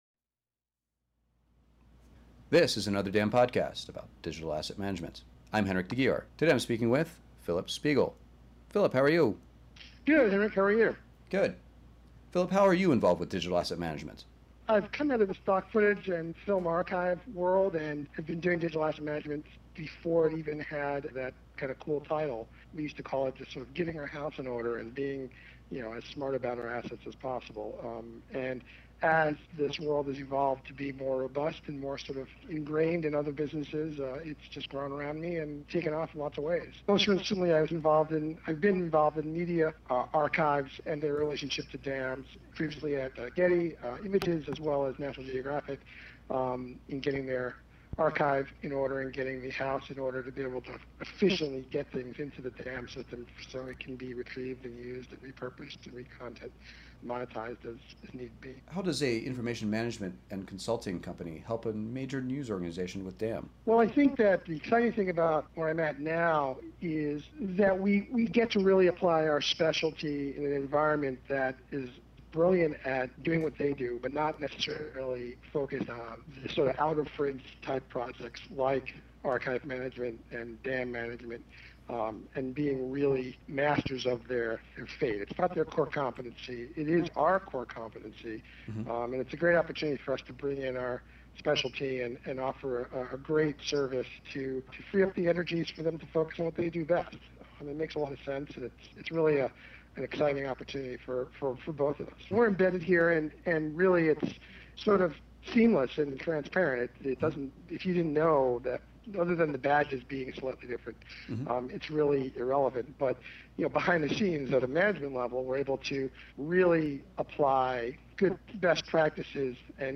Another DAM Podcast interview